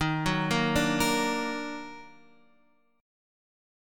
D#mM7 chord